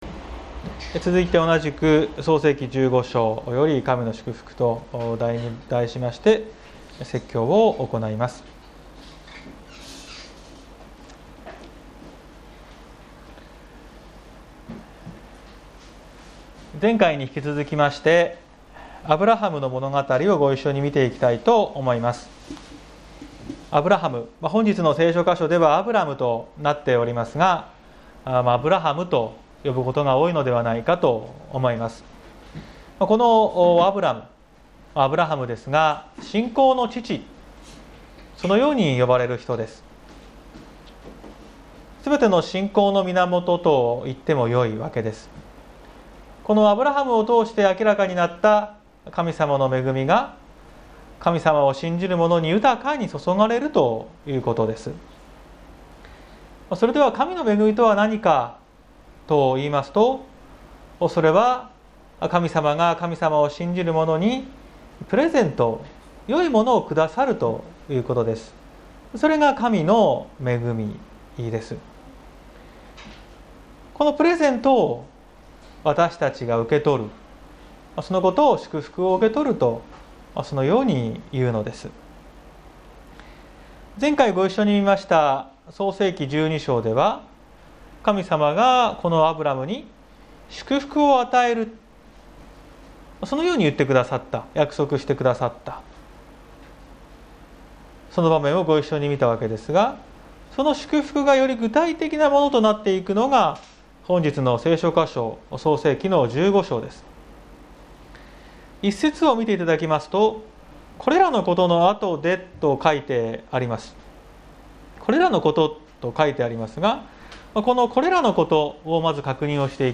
2021年10月10日朝の礼拝「神の祝福」綱島教会
綱島教会。説教アーカイブ。